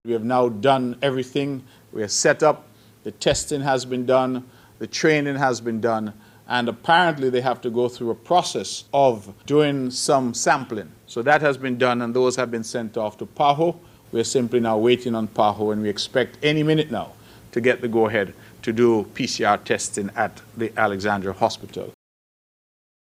Premier of Nevis and Senior Minister of Health, the Hon. Mark Brantley.
He was giving an update on Polymerase Chain Reaction (PCR) Testing at the Alexandra Hospital.